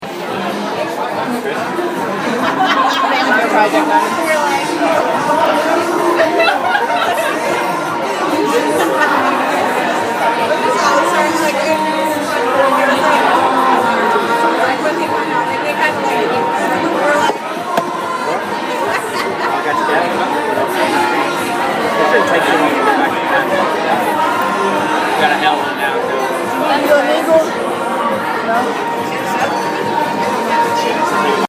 Field Recording #9
Sounds Heard: People talking, laughing, music, silverwear clinking, ice in classes, people coughing